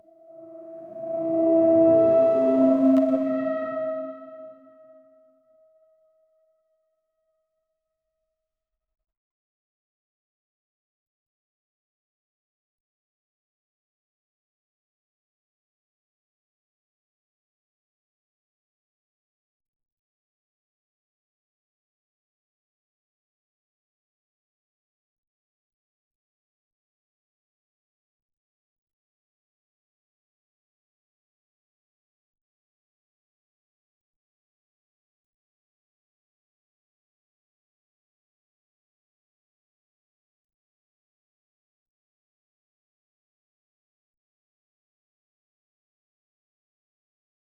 Anime style. 0:10 The sound of Sailor Moon's Moonlight Power when she responds 0:10 SFX de machine en fonctionnement, avec des grondements mecaniques, un son grave avec des bruits aigu mecaniques.
the-sound-of-sailor-moons-lo6vtnag.wav